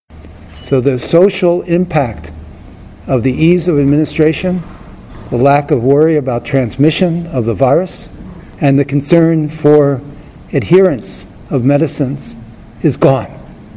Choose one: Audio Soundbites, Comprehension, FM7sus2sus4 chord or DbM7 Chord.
Audio Soundbites